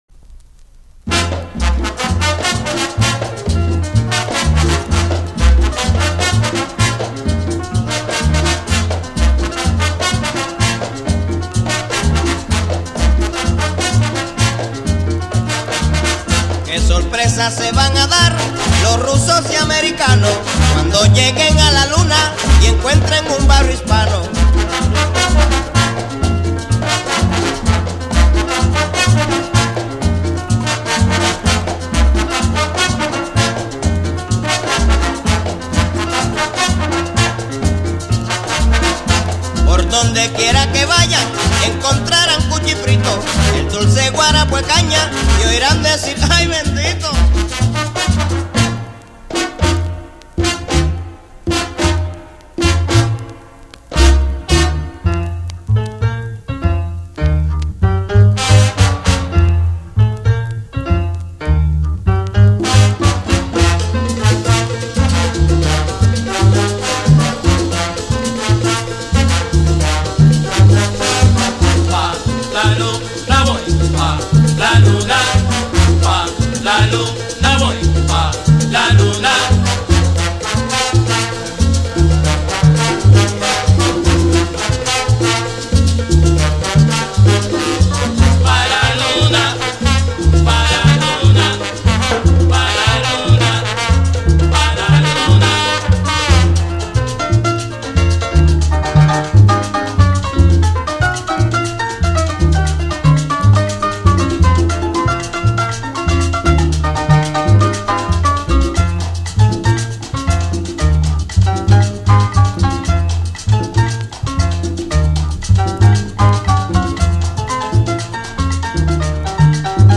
Это строго говоря не salsa, а plena.